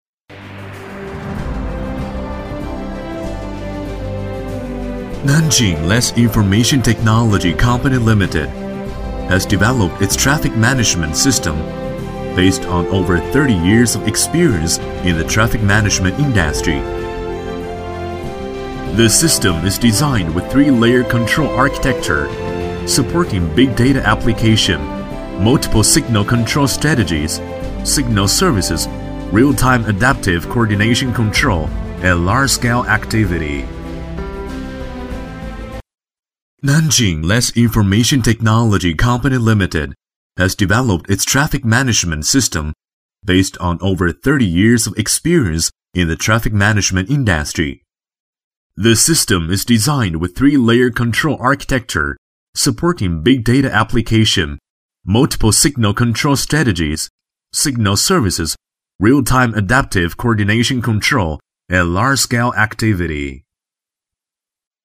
男40号配音师
十余年从业经验，精通中文，日文，英文，声音浑厚，庄重，大气。
英文-男40-南京莱斯 企业宣传.mp3